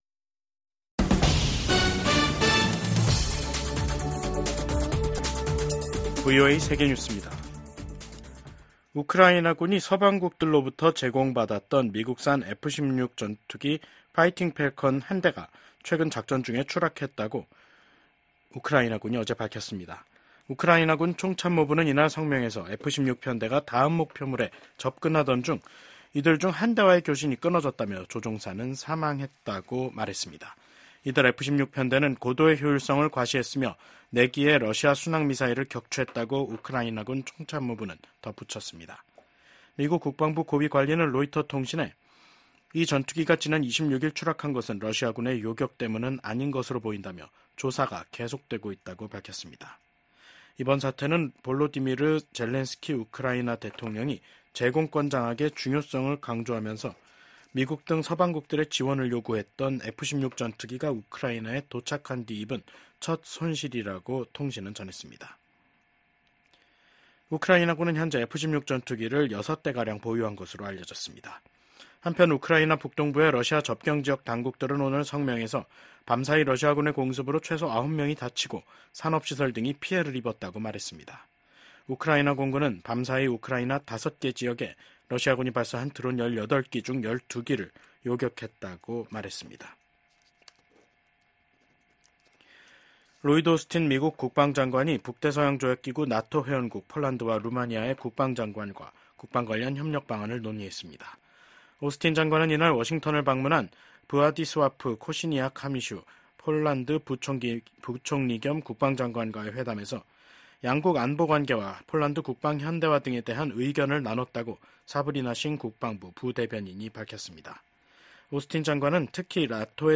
VOA 한국어 간판 뉴스 프로그램 '뉴스 투데이', 2024년 8월 30일 3부 방송입니다. 북한 해군 자산들이 국제해사기구(IMO) 자료에서 사라지고 있습니다.